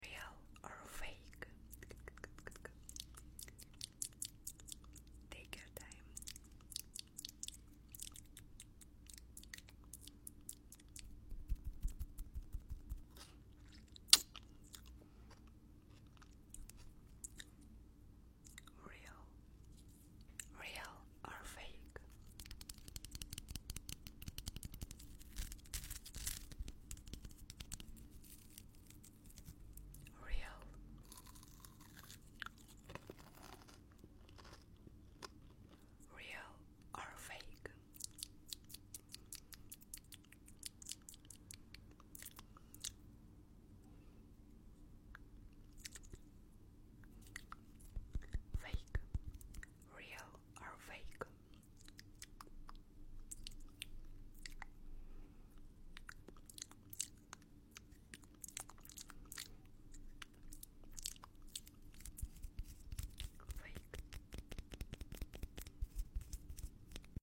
ASMR: Real Or Fake ? Sound Effects Free Download